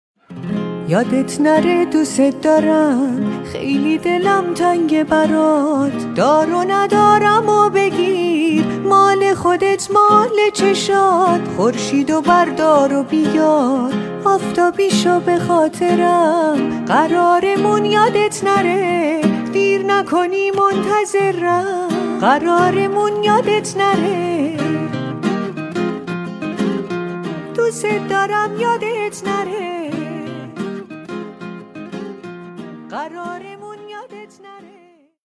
با صدای زن (تمام خوانندگان)